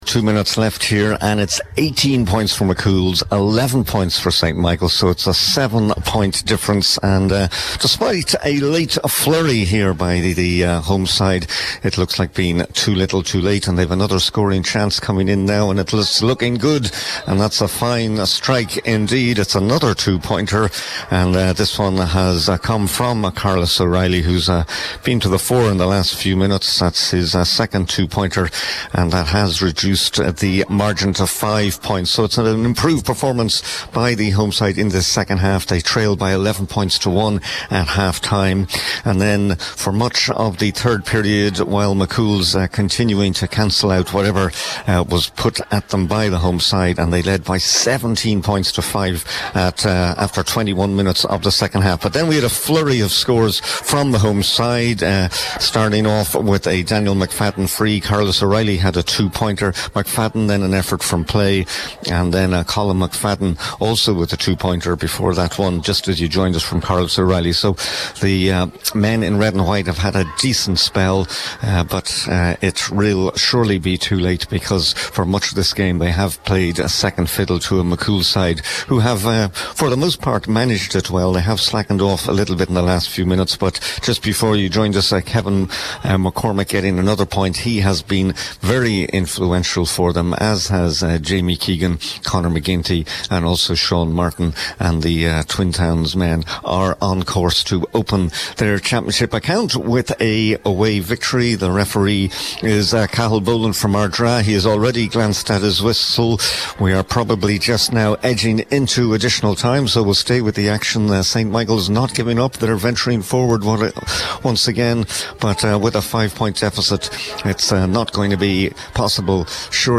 live at full time in Dunfanaghy…